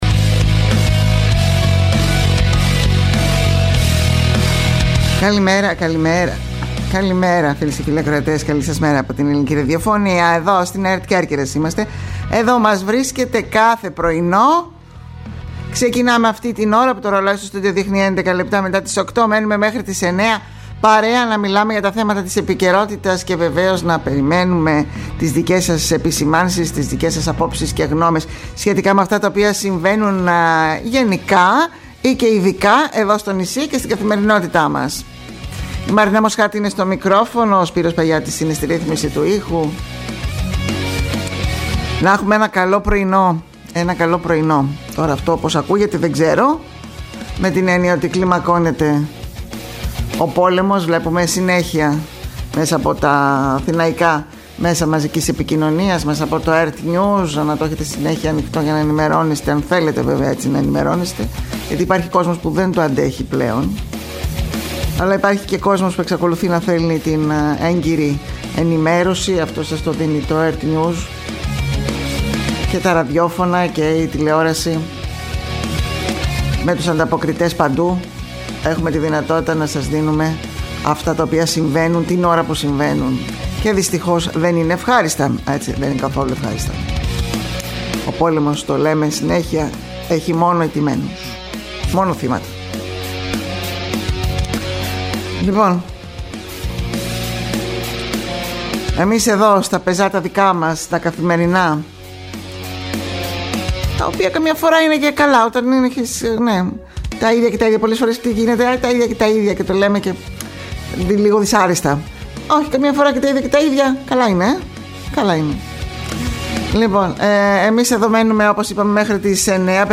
Η ενημερωτική ζώνη της ΕΡΤ Κέρκυρας που παρουσιάζει, αναλύει και σχολιάζει την τοπική επικαιρότητα.